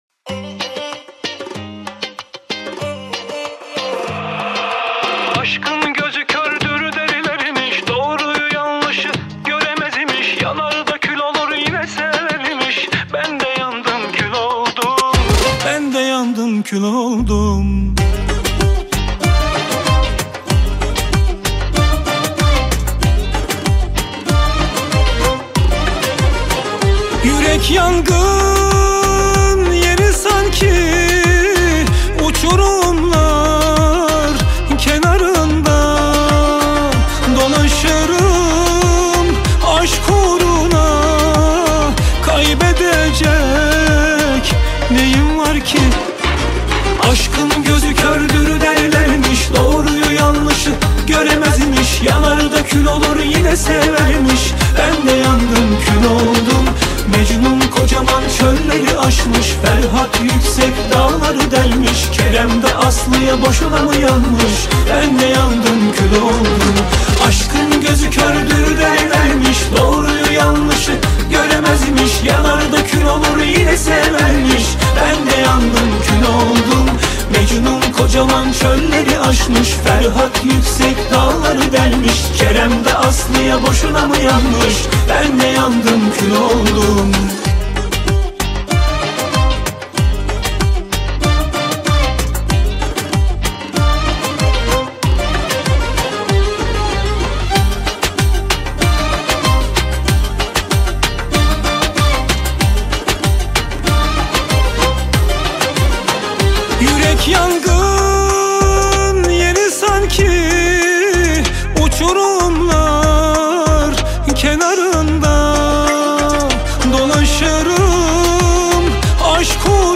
Турецкие песни